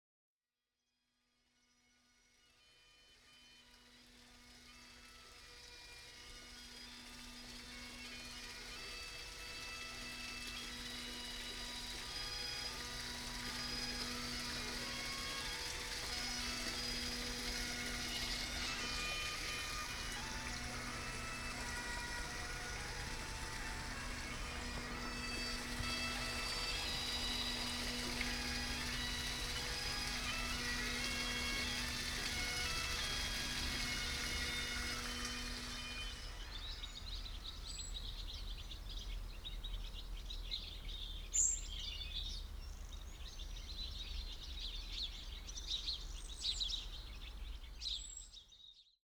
Bagpipes and Birds - Full Recording The full recording is 49 seconds in length At the opening, bagpipes dominate and the sound of water can be heard in the background. Towards the end, birdsong is most prominent Raw audio, no processing Recorded 2010 using binaural microphones
Bagpipes and Birds, Full Recording.wav